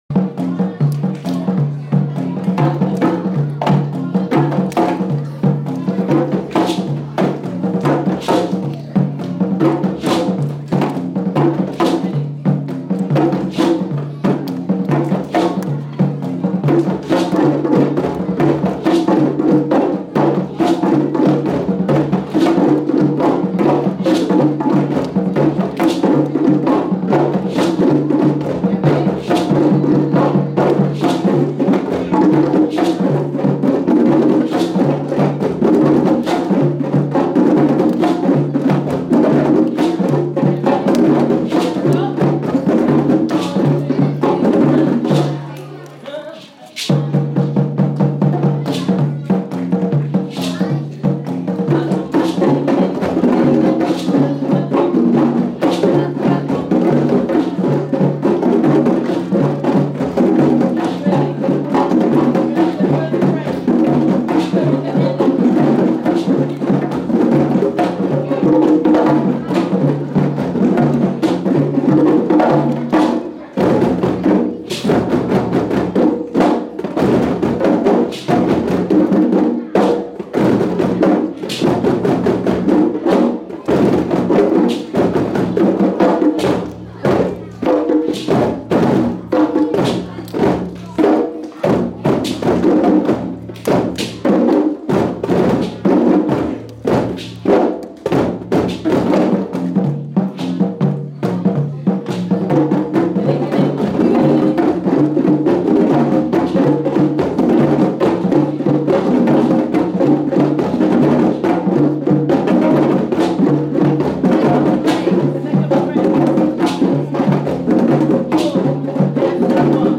Year 6 Drumming Concert